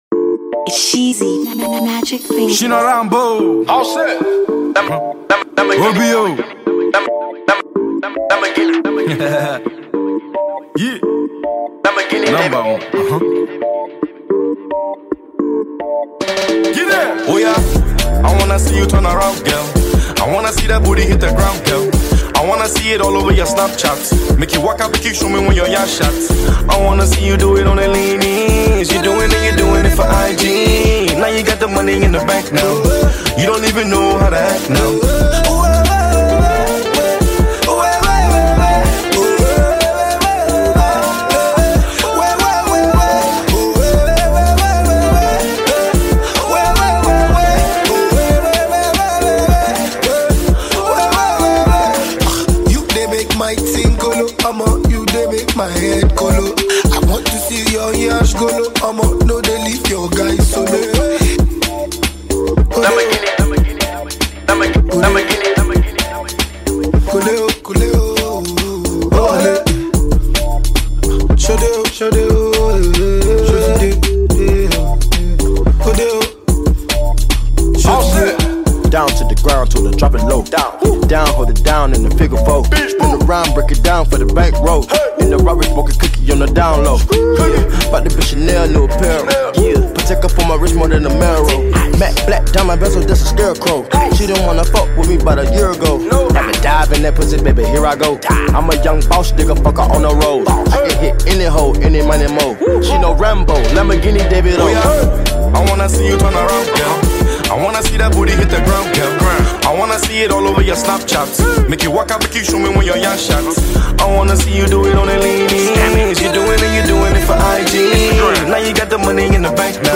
Nigerian rapper
heavy tune
On this smooth combination of Afro-pop and Hip-Hop